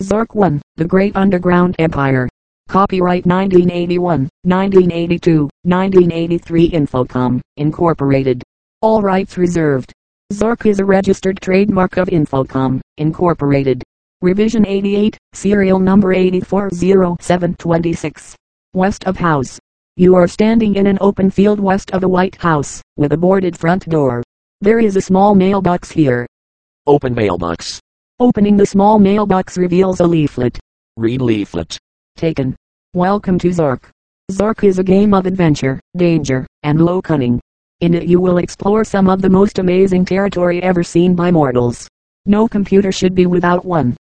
It also features limited support for speech synthesis and recognition under Win32 (
listen to a sample MP3 of speech output).
rezrov_speech_zork1.mp3